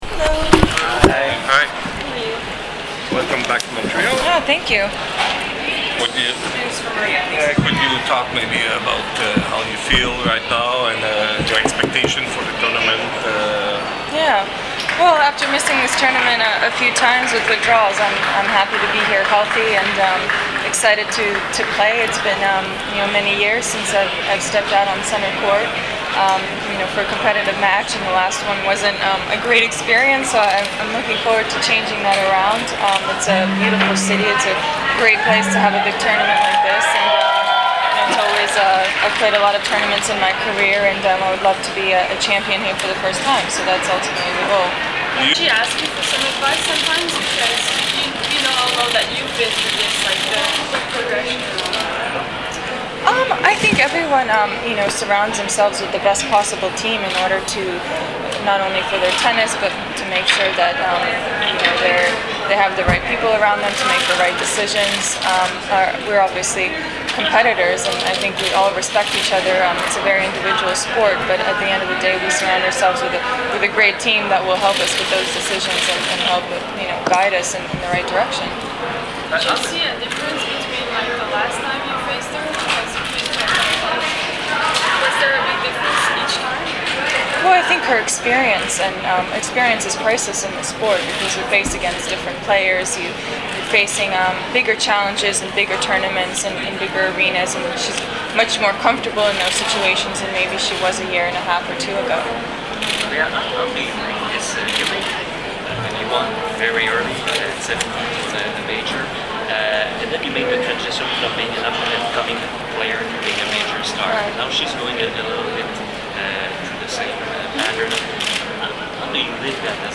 TENNIS – Le interviste pre-torneo di alcune delle protagoniste di questa edizione della Rogers Cup: Sharapova, Bouchard, Azarenka e Jankovic